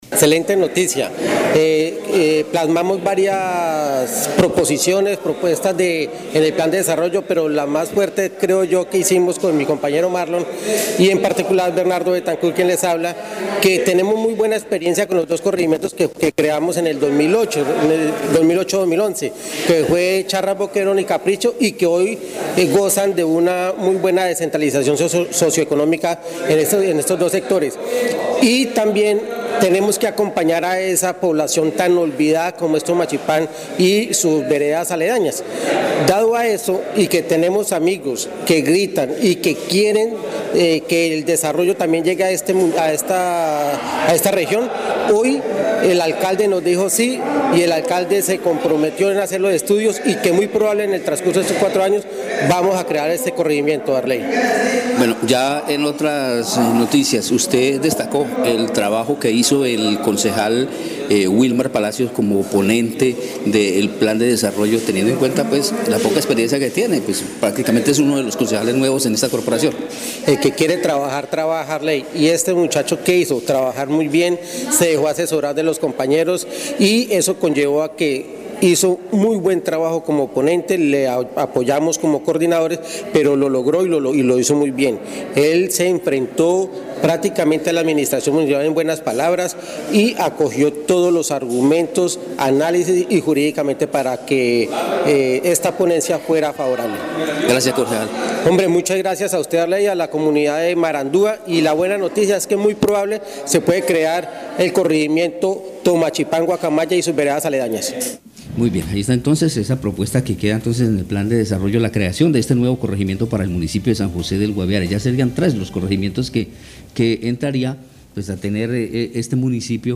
Escuche a Bernardo Betancourth y Wilmar Palacios, concejales de San José del Guaviare.